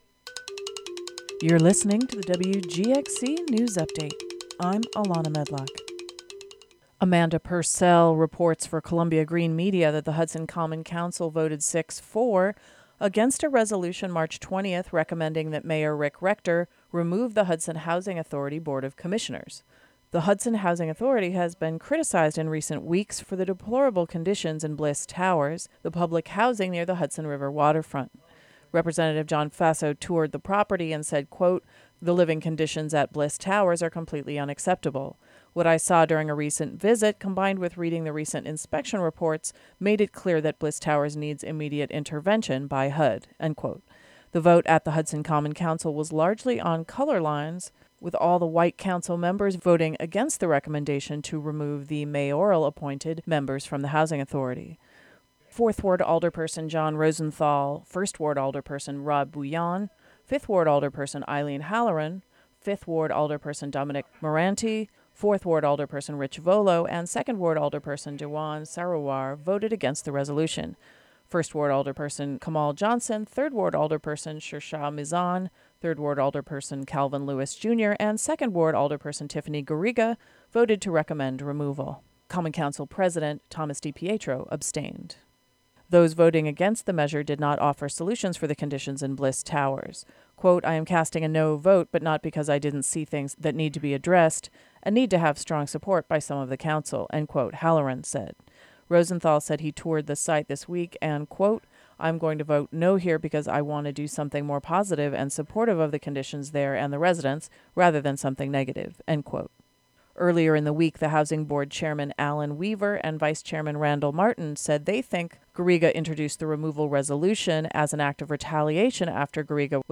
The local news update for the Hudson Valley.